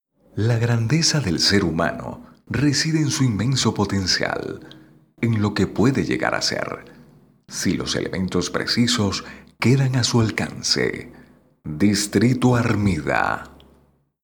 Locutor profesional con experiencia de 10 años en conduccion produccion de programas de radio.Experiencia en narracion de documentales, comerciales e identificacion para agencias de publicidad.
spanisch Südamerika
Sprechprobe: Werbung (Muttersprache):